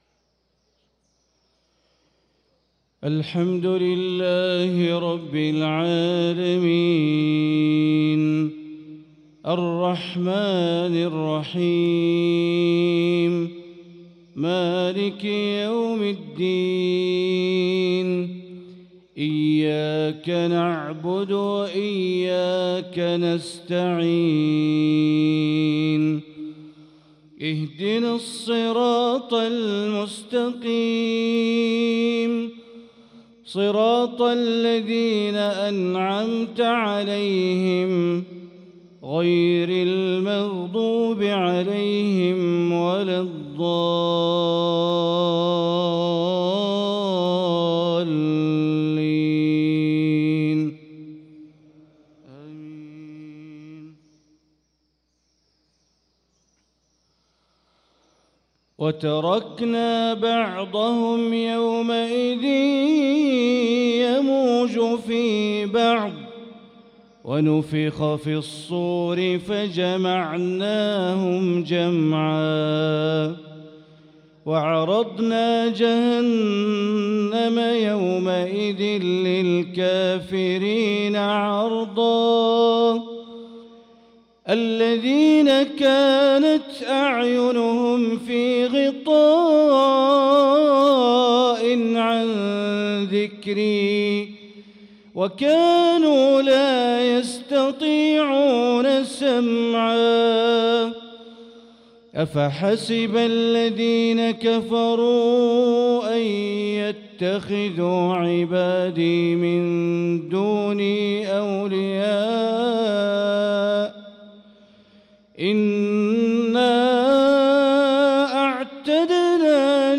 صلاة المغرب للقارئ بندر بليلة 28 رجب 1445 هـ